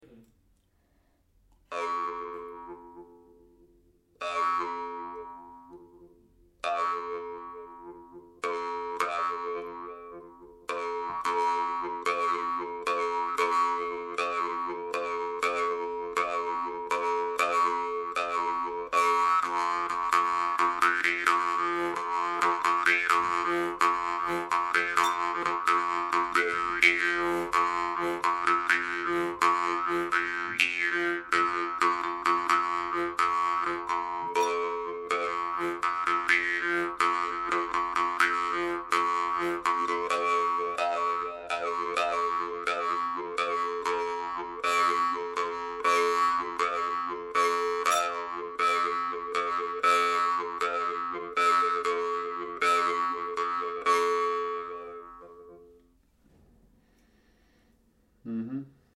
Комментарий: Якутский хомус безупречной обработки, очень сильный звук.
Это в свою очередь позволяет выставлять очень узкий зазор между язычком хомуса и его деками, добиваясь при этом очень сочных обертонов по всему спектру звучания.
Домашняя студия.